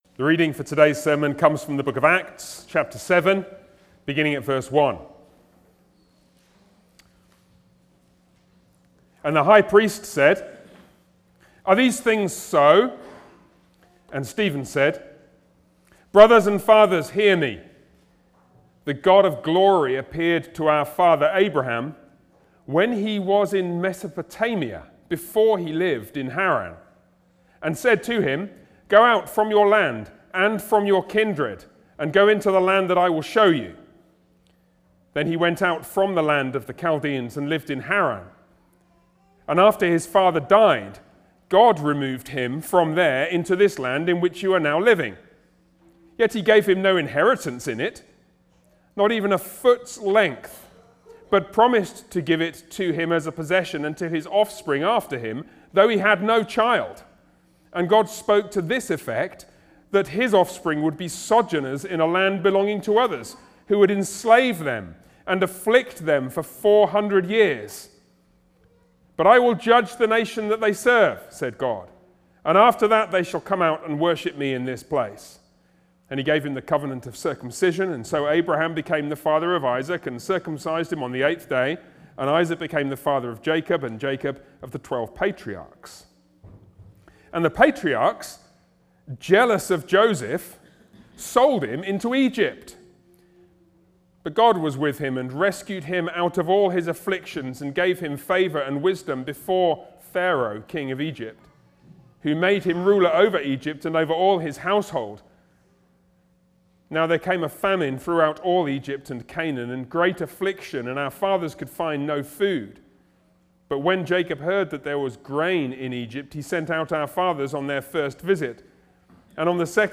Sermons on Acts
Service Type: Sunday worship